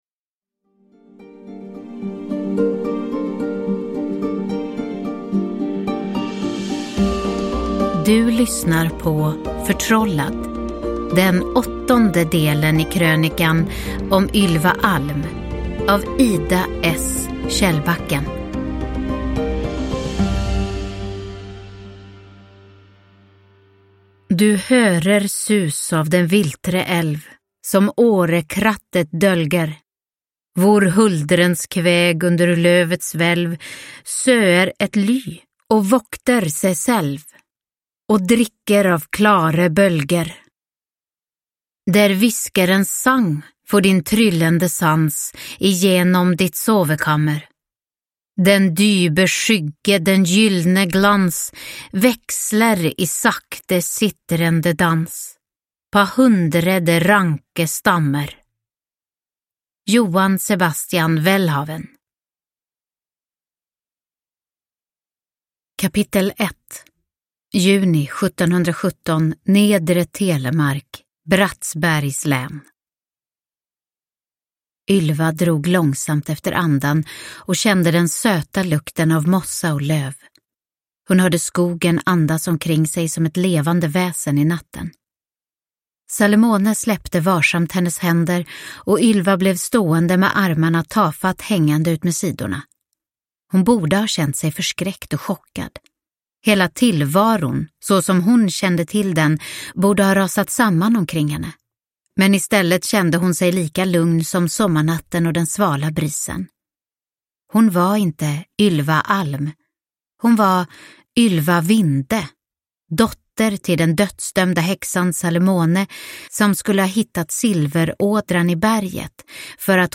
Förtrollad – Ljudbok